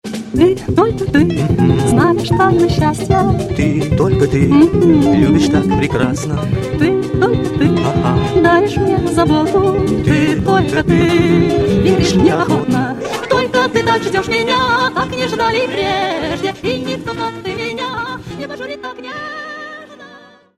• Качество: 128, Stereo
дуэт